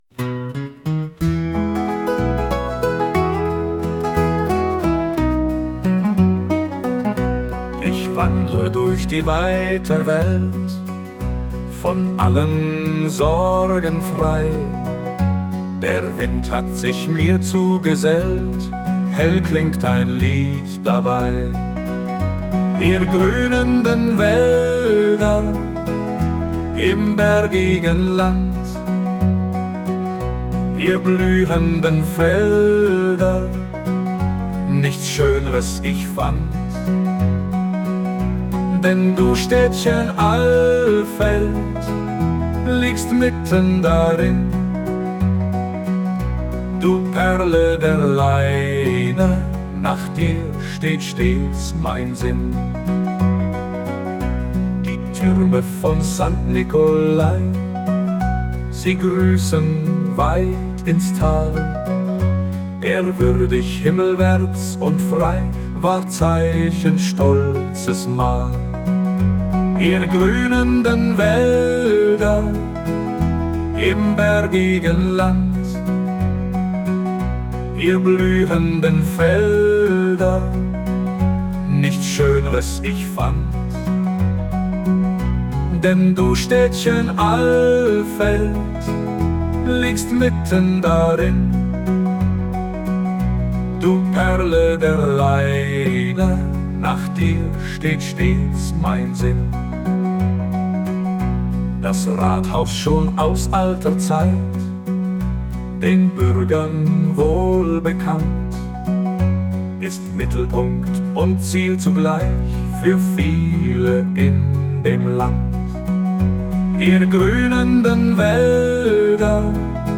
Heute spielt das Glockenspiel am Bürgeramt gelegentlich das „Alfeld-Lied“